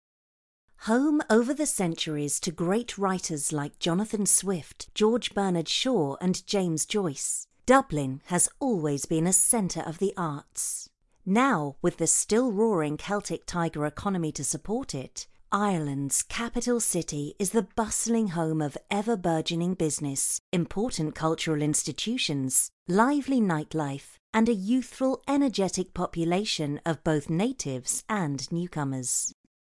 Engels (Brits)
Natuurlijk, Speels, Veelzijdig, Vriendelijk, Warm
E-learning